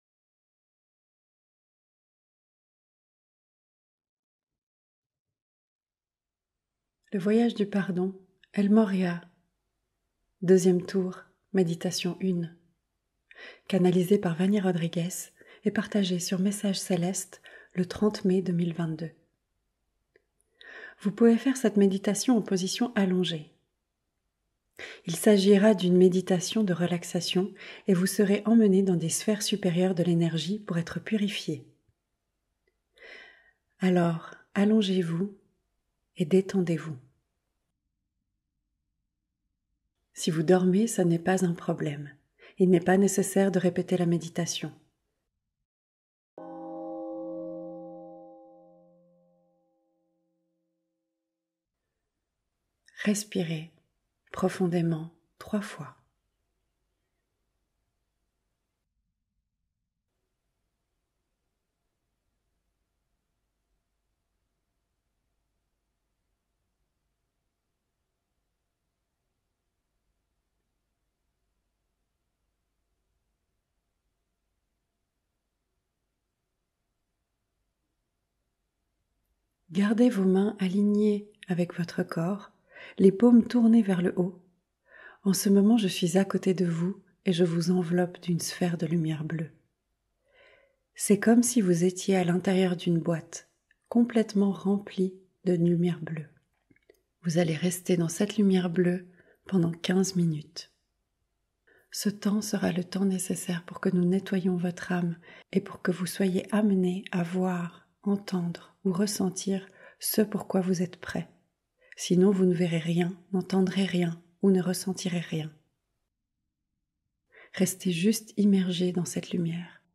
Tour 2 - Méditation 1 - sans_pub